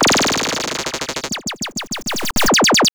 Glitch FX 22.wav